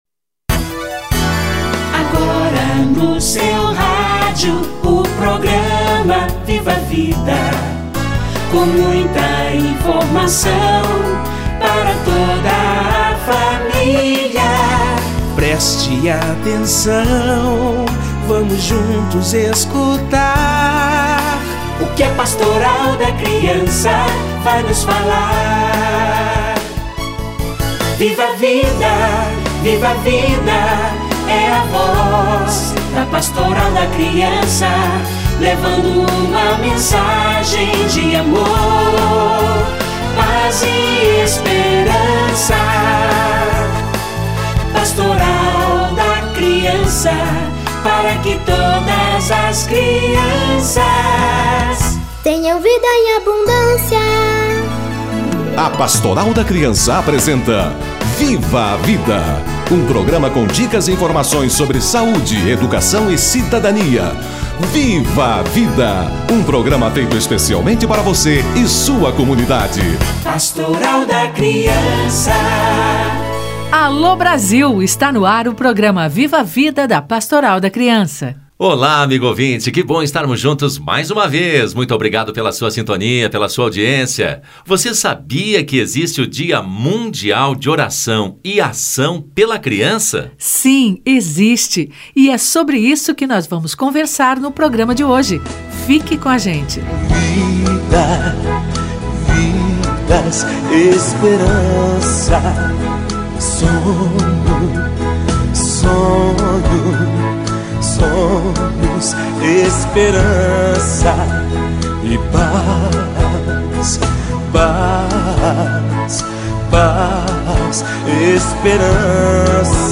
Oração e Ação pela Criança - Entrevista